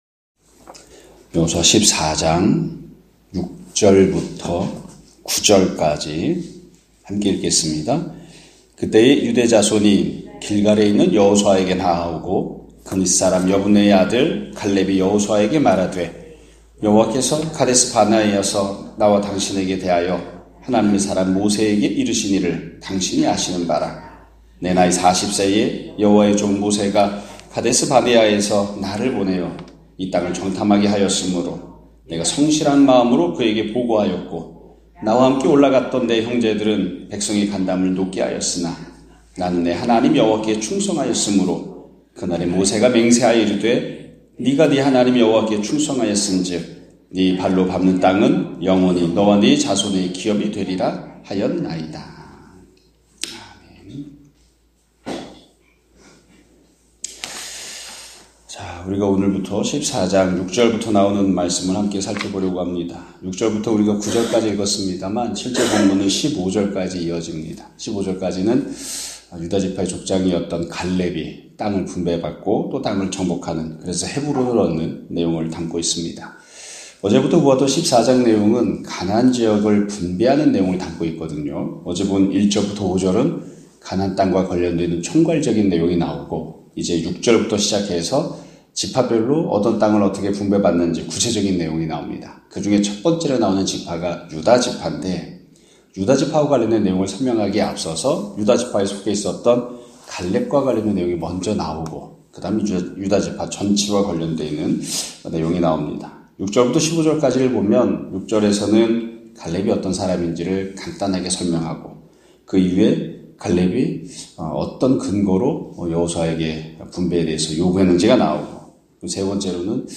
2024년 11월 26일(화요일) <아침예배> 설교입니다.